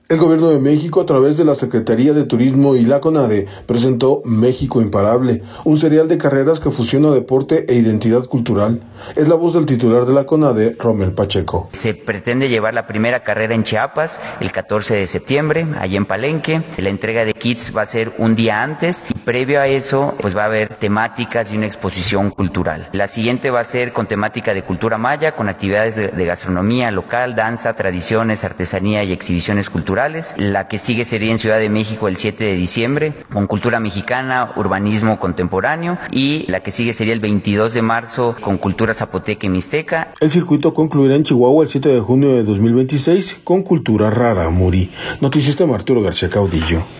El Gobierno de México, a través de la Secretaría de Turismo y de la Conade, presentó México Imparable, un serial de carreras que fusiona deporte e identidad cultural. Es la voz del titular de la Conade, Rommel Pacheco.